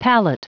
Prononciation du mot palette en anglais (fichier audio)
Prononciation du mot : palette